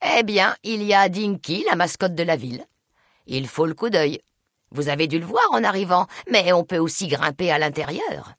Jeannie May Crawford décrivant Dinky le T-Rex dans Fallout: New Vegas.